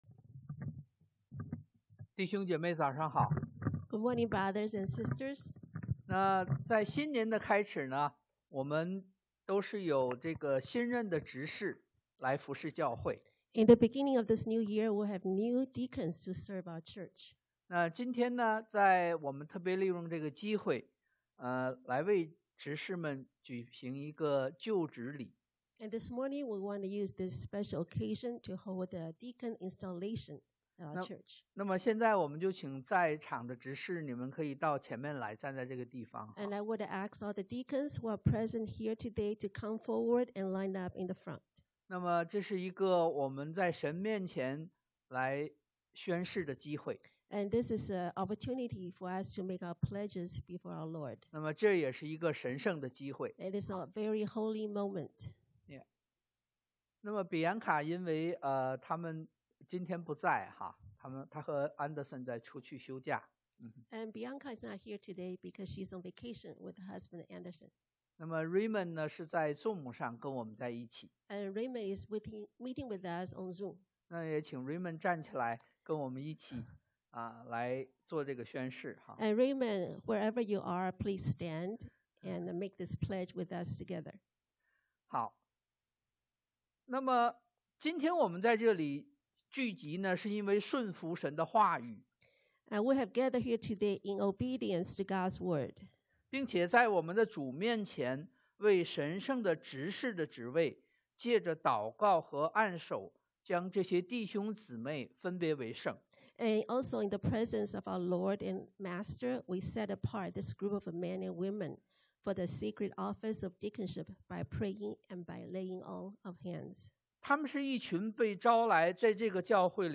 彼前 1 Peter 1:13-25 Service Type: Sunday AM The Pursuit of Holiness 追求聖潔 Passage